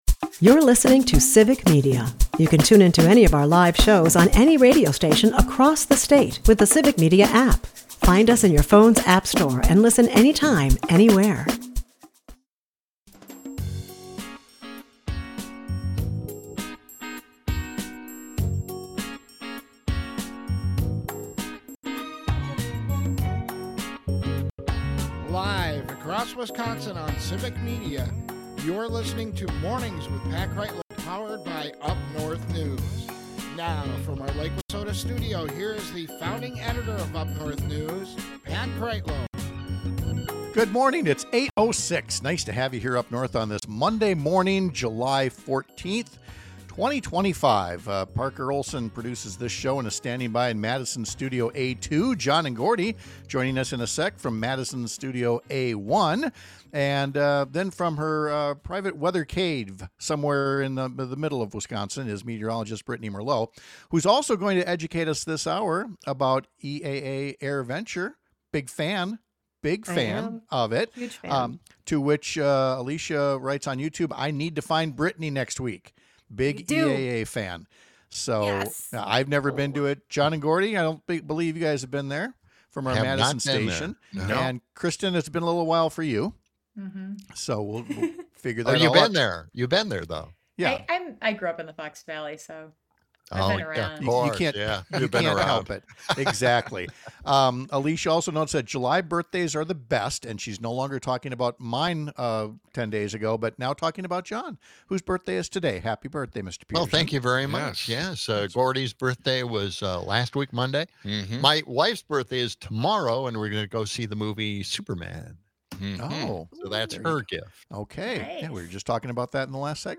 We’ll visit with state Rep. Amaad Rivera-Wagner about what legislators will be working on, now that the state budget has been completed.